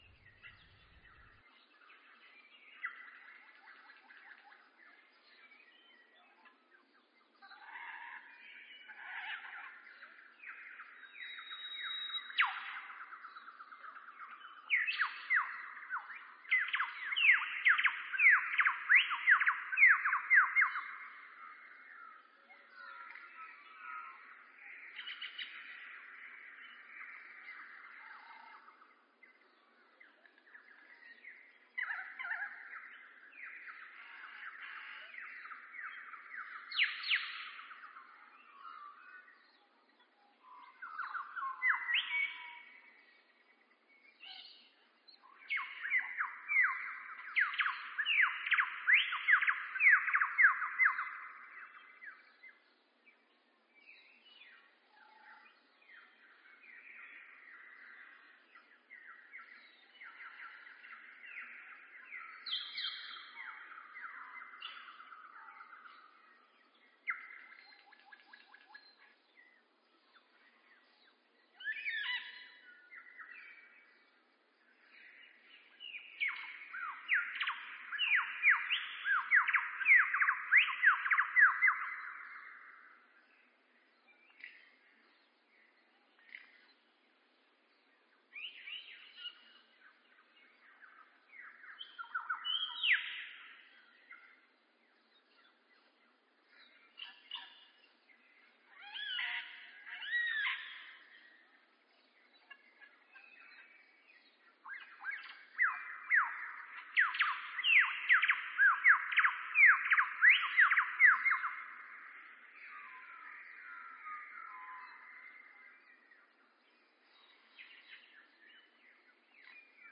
I listened to an extraordinary 30 minute soundscape of Jasper’s Brush and marvelled at the variety and beauty of the sound.